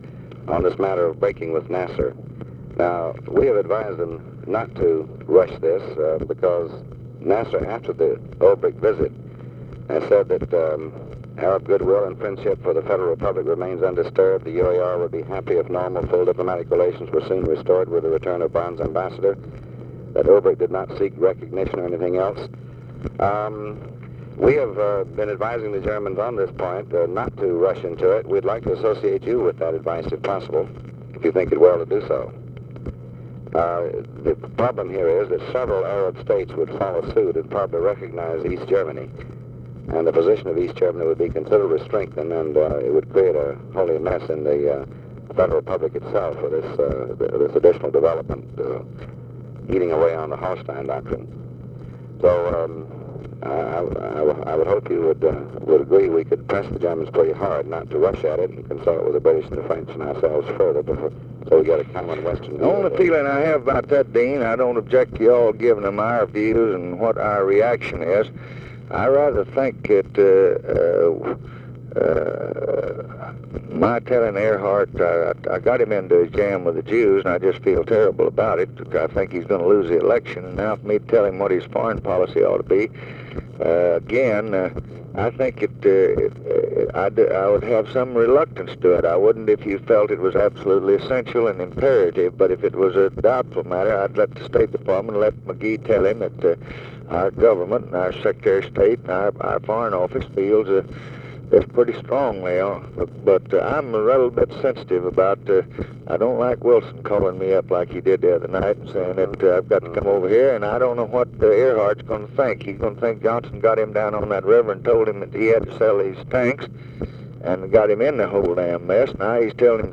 Conversation with DEAN RUSK, March 5, 1965
Secret White House Tapes